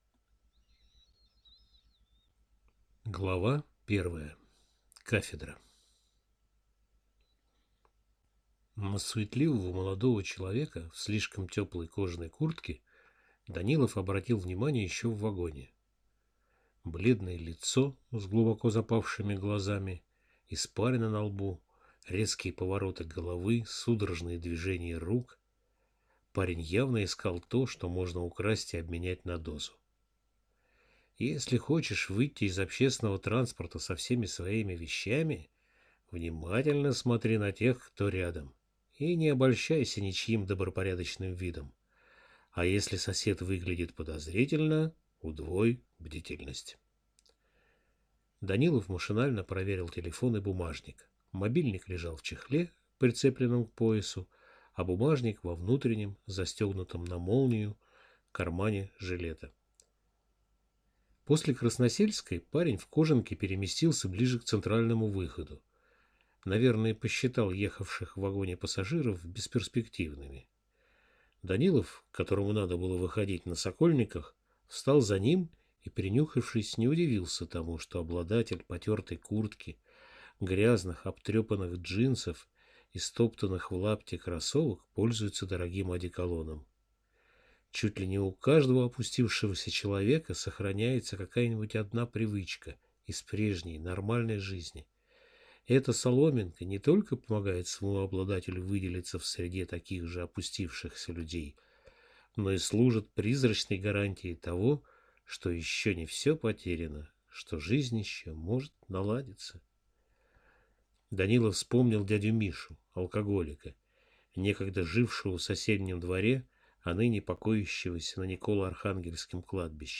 Аудиокнига Доктор Данилов в морге, или Невероятные будни патологоанатома - купить, скачать и слушать онлайн | КнигоПоиск